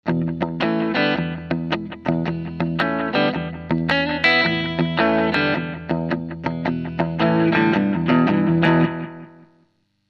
Аналоговый дилэй Electro-Harmonix Deluxe Memory Man
Shuffle Slapback.mp3 (118 кБ)
shuffle_slapback.mp3